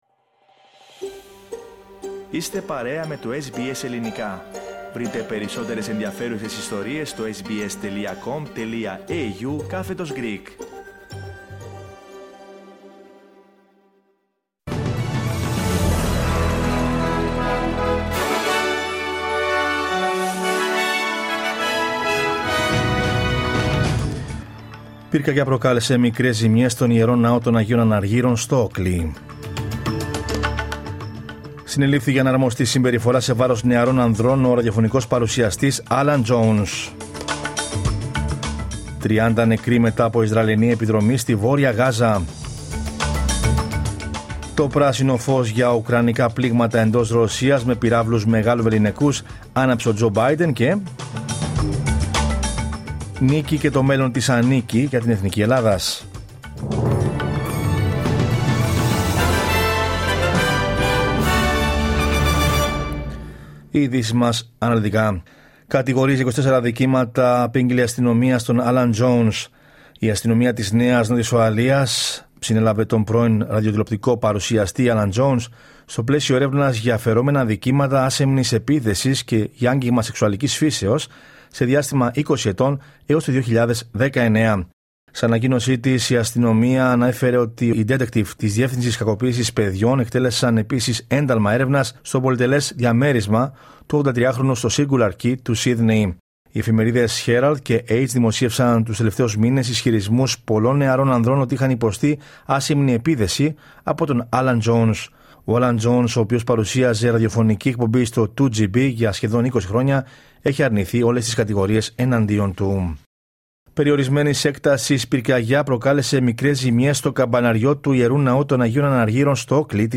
Δελτίο Ειδήσεων Δευτέρα 18 Νοεμβρίου 2024